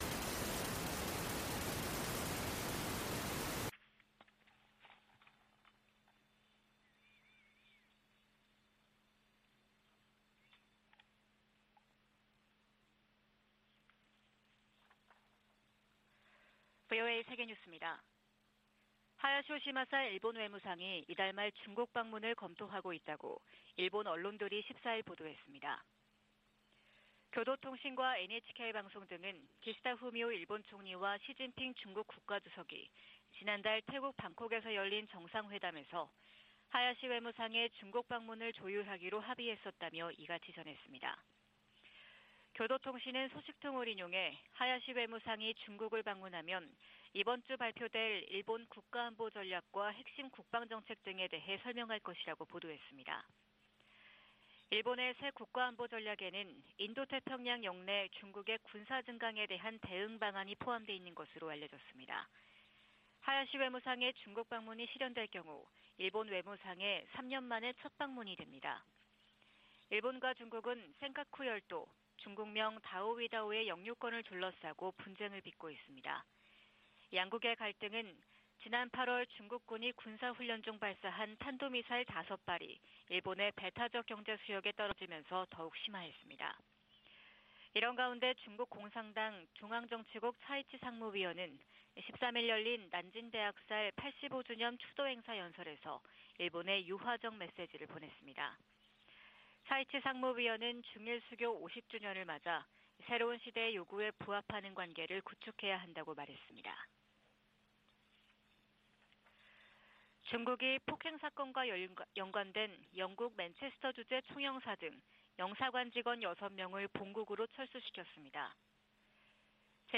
VOA 한국어 '출발 뉴스 쇼', 2022년 12월 15일 방송입니다. 미국 국무부가 유럽연합(EU)의 대북 추가 독자제재 조치를 높이 평가하면서 북한 정권에 책임을 물리기 위해 동맹, 파트너와 협력하고 있다고 밝혔습니다. 북한의 인도주의 위기는 국제사회의 제재 때문이 아니라 김정은 정권의 잘못된 정책에서 비롯됐다고 유엔 안보리 대북제재위원장이 지적했습니다.